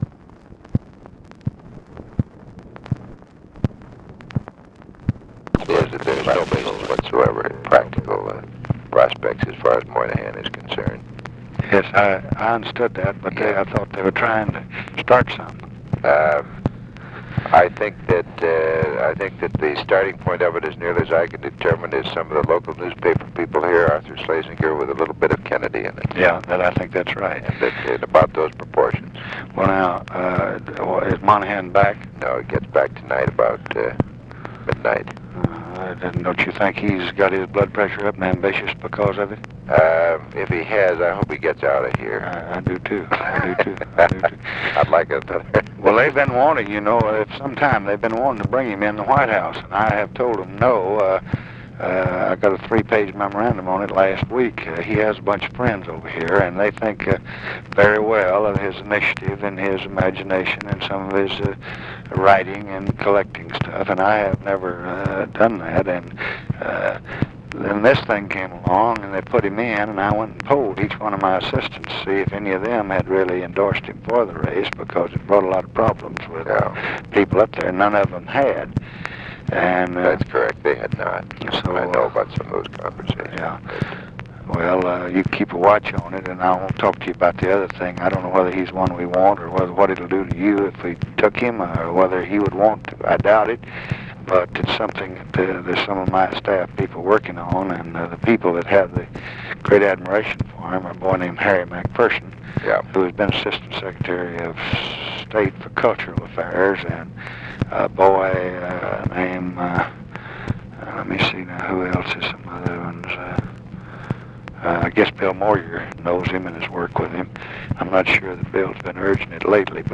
Conversation with WILLARD WIRTZ, June 24, 1965
Secret White House Tapes